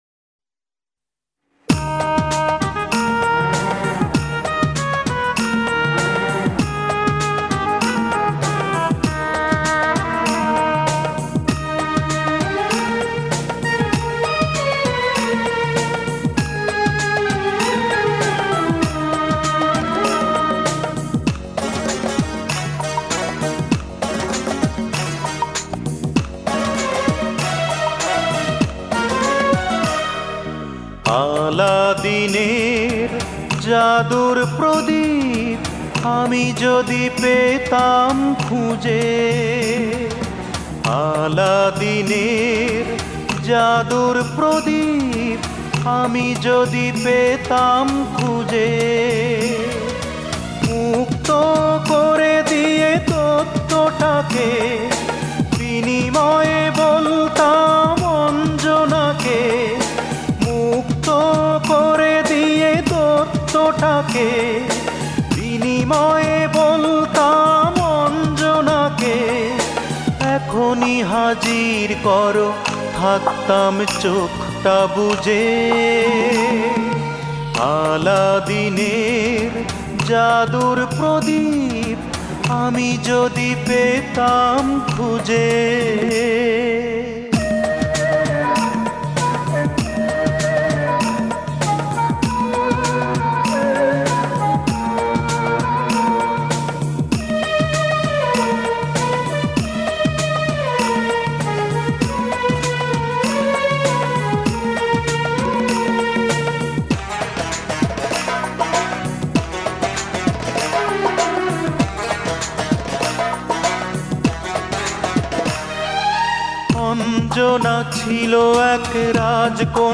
Genre Adhunik Bangla